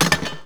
Metal_hit_1.wav